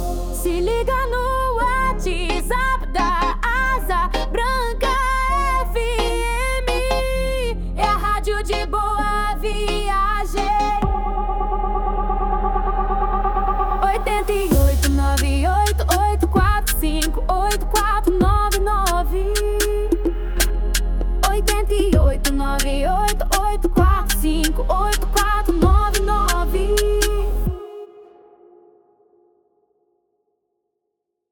JINGLE PUBLICITÁRIO
Pop Funk Rádio WhatsApp